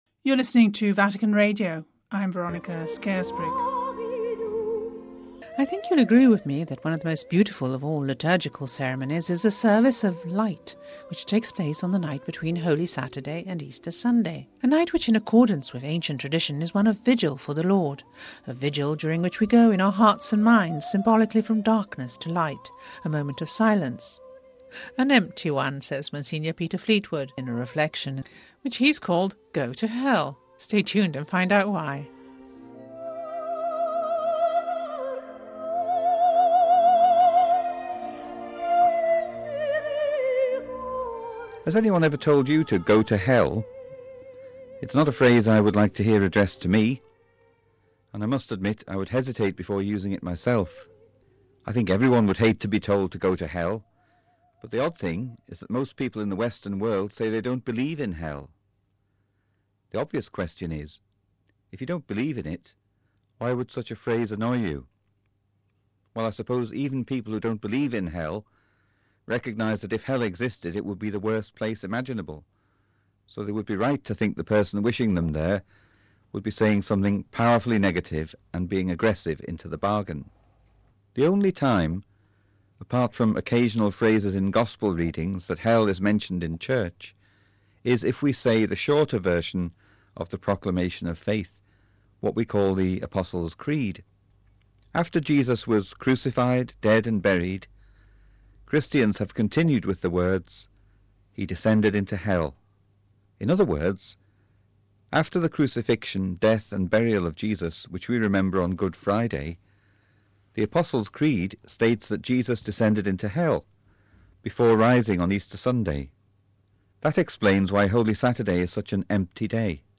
We bring you a reflection for Easter Saturday